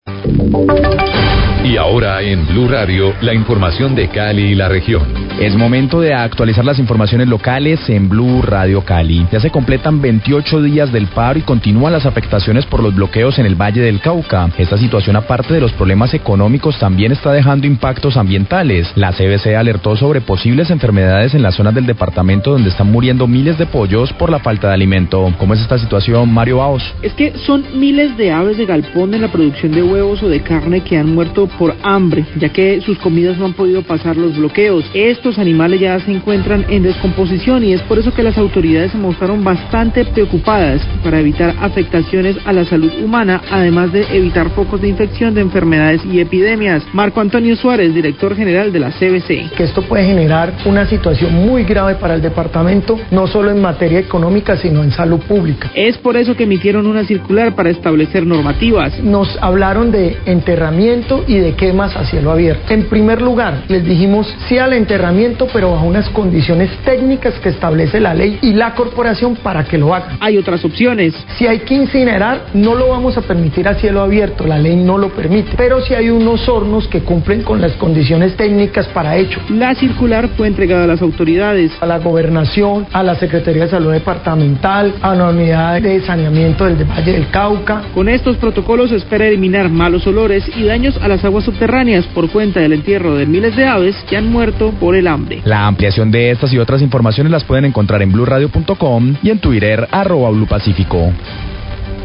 Radio
CVC advierte sobre el riesgo ambiental y a la salud pública por la muerte de miles de pollos debido a la falta de alimentos por los bloqueos de vías del Paro Nacional. Director general de la CVC; Marco Antonio Suárez, habla de las condiciones para la disposición final de estos animales.